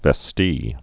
(vĕ-stē)